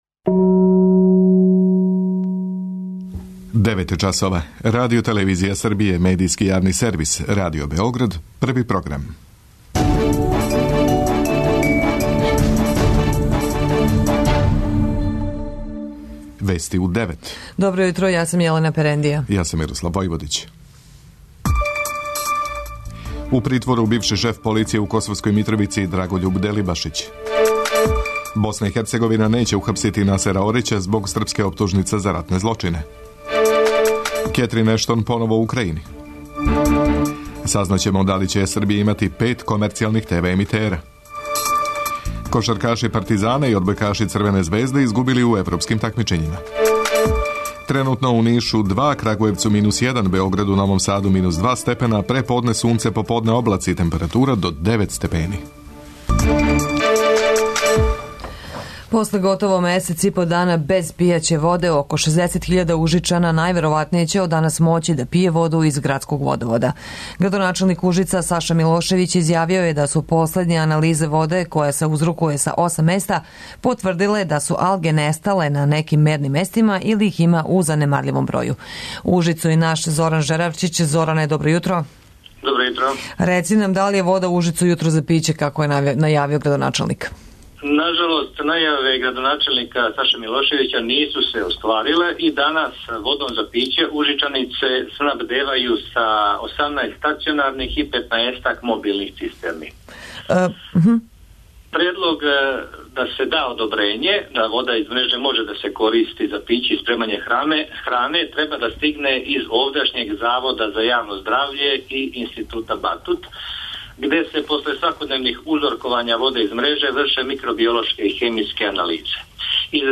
преузми : 10.07 MB Вести у 9 Autor: разни аутори Преглед најважнијиx информација из земље из света.